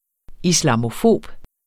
Udtale [ islɑmoˈfoˀb ]